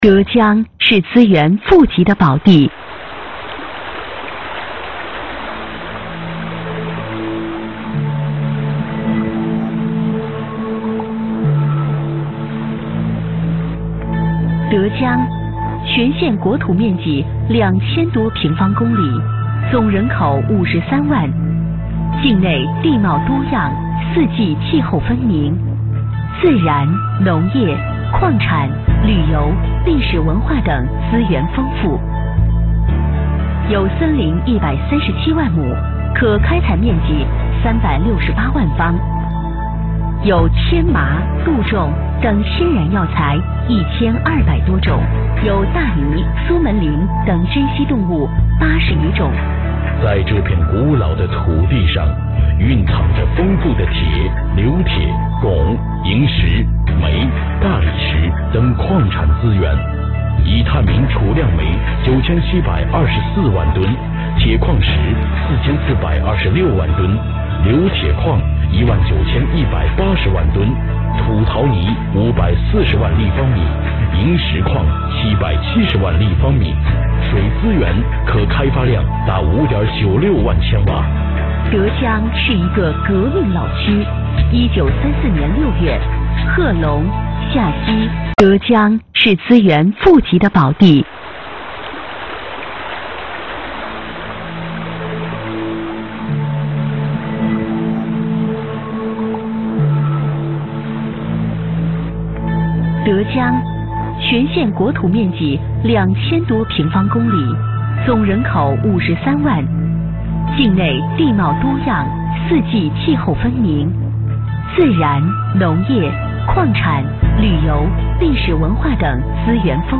职业配音员全职配音员柔和清脆
• 女S112 国语 女声 专题 德江城市宣传片 沉稳|科技感|积极向上|亲切甜美